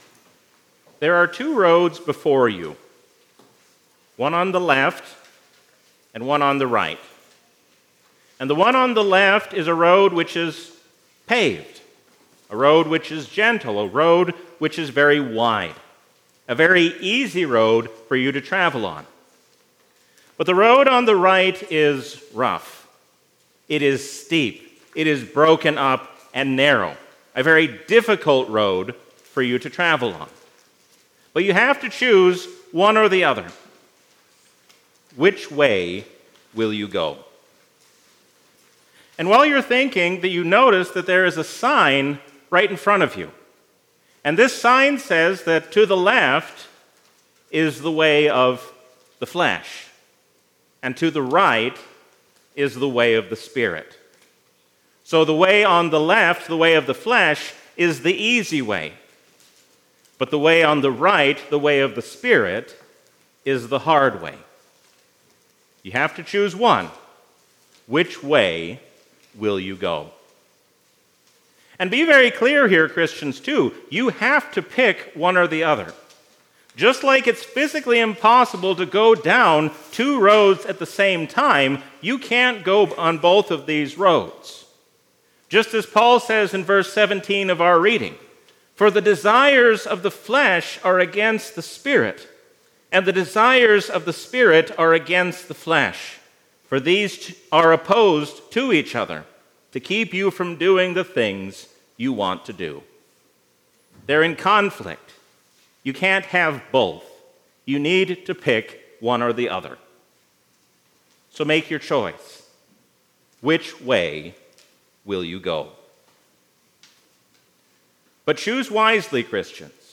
A sermon from the season "Trinity 2024." Look to Jesus who sets you on the way of life, and you will find blessing both now and in eternity.